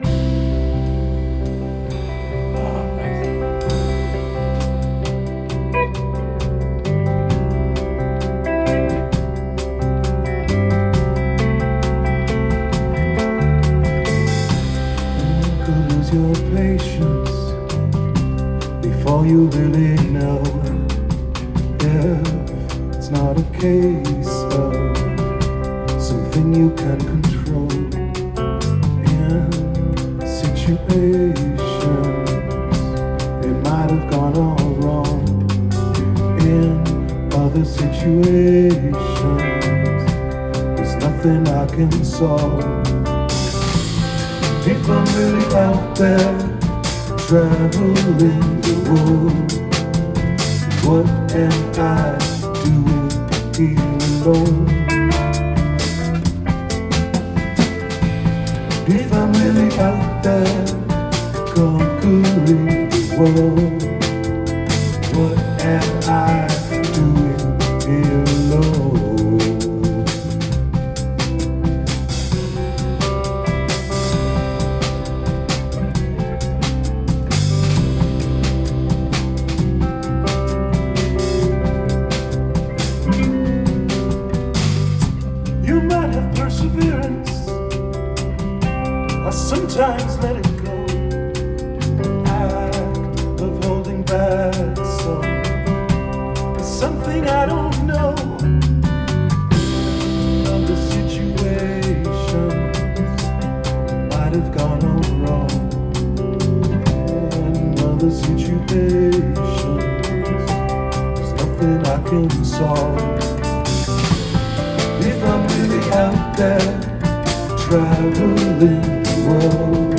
Rehearsals 6.9.2013